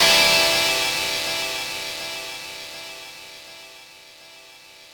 ChordCadd9.wav